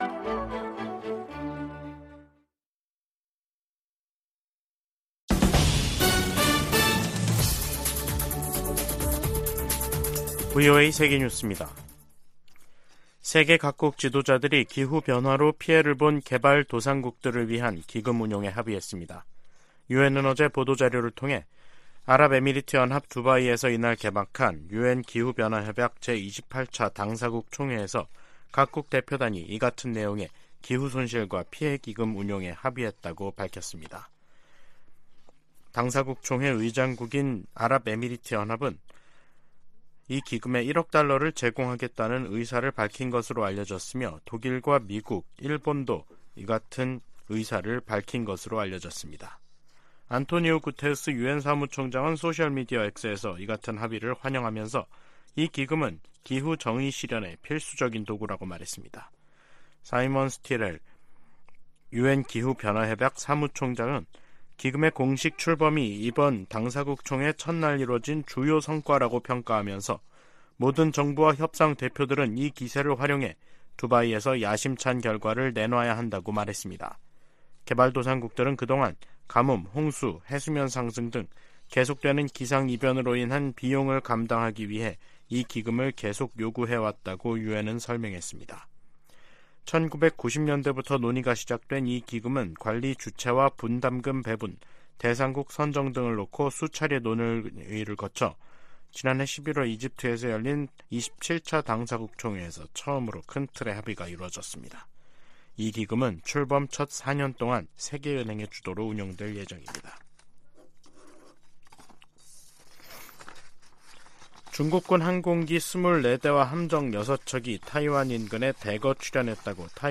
VOA 한국어 간판 뉴스 프로그램 '뉴스 투데이', 2023년 12월 1일 2부 방송입니다. 미국 정부가 북한의 군사 정찰위성 발사에 대한 대응 조치로 북한 국적자 8명과 기관 1곳을 전격 제재했습니다. 한국 정부가 북한의 군사정찰위성 개발 등에 관련한 북한 사람들에 독자 제재를 발표했습니다. 북한이 유엔 안보리를 선전 도구로 이용하고 있다고 유엔 주재 미국대표부가 비판했습니다.